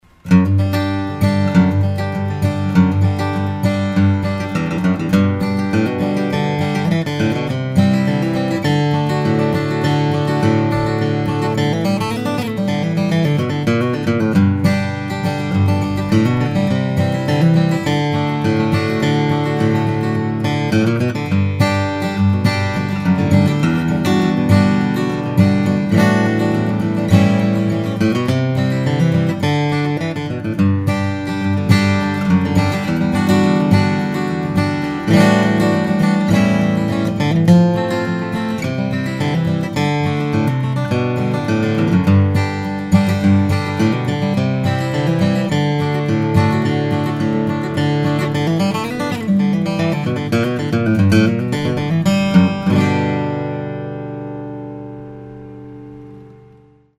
This one really sings. The playability is great and this one is a true cannon of a guitar.